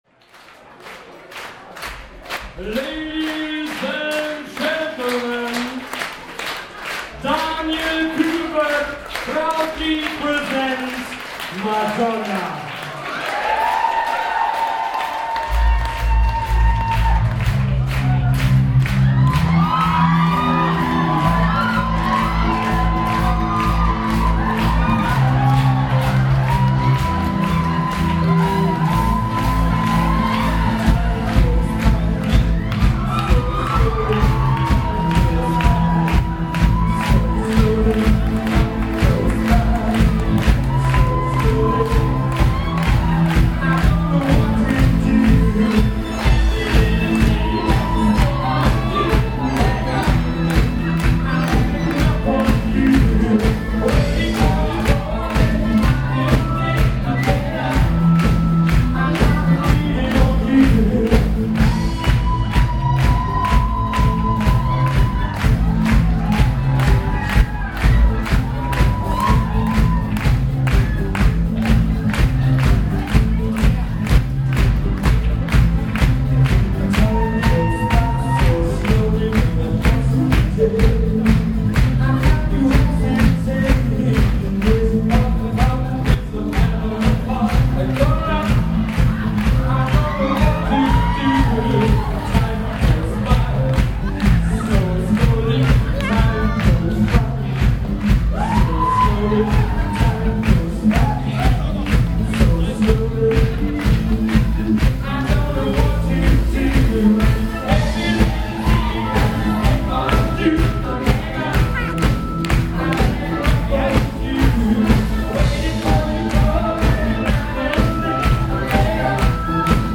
Auftaktkonzert
Piano
Bass
Saxophon
Schlagzeug
Gitarre
Background-Gesang